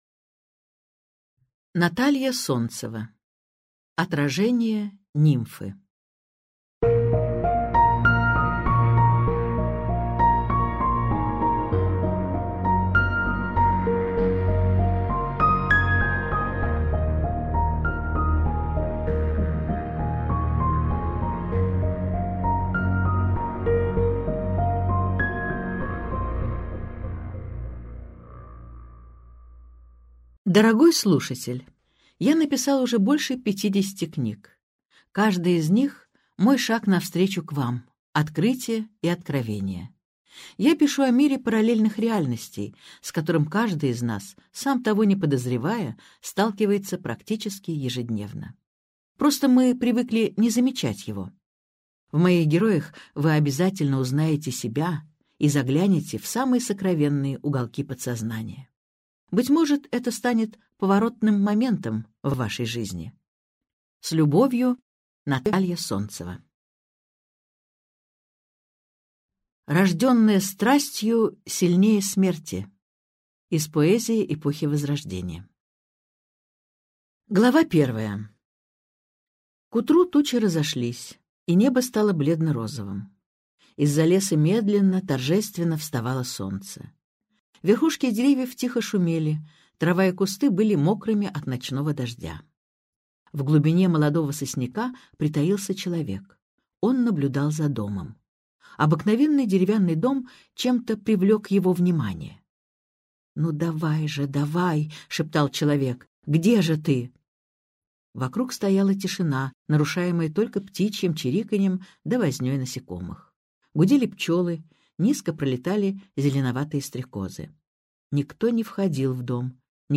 Аудиокнига Отражение нимфы | Библиотека аудиокниг